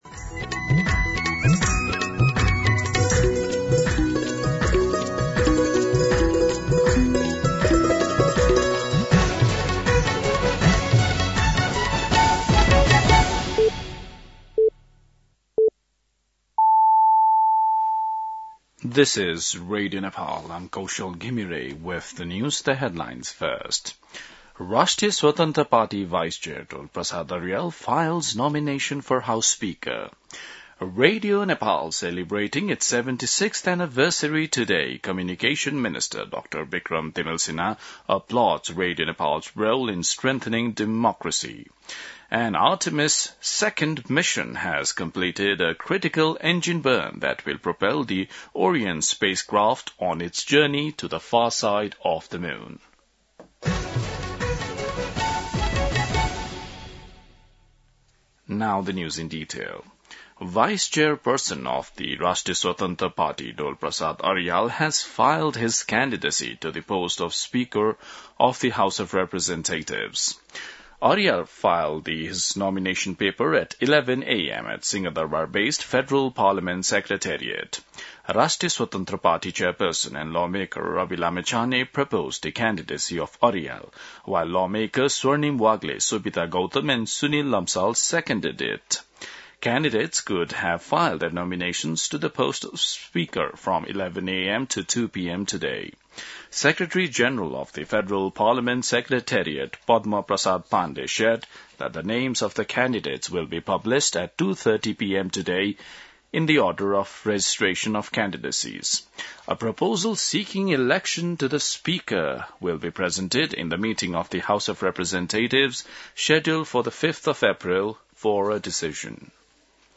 दिउँसो २ बजेको अङ्ग्रेजी समाचार : २० चैत , २०८२